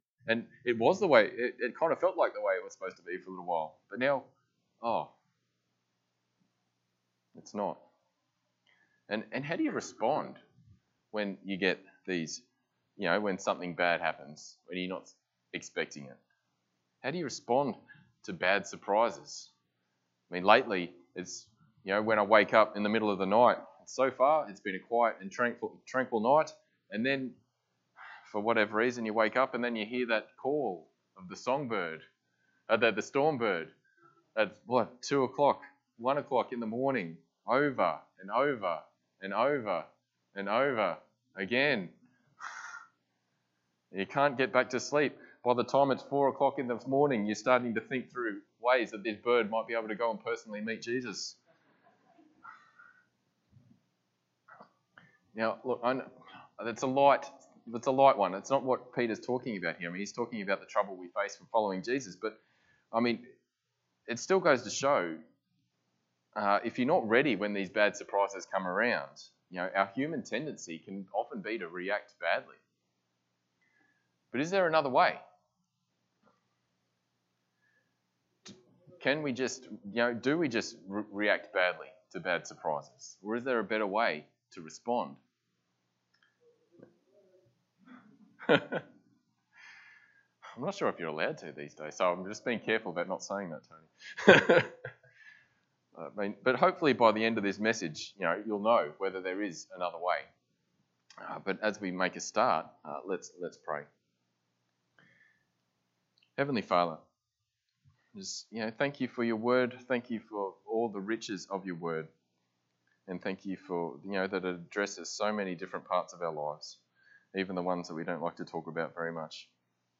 Passage: 1 Peter 4:12-19 Service Type: Sunday Morning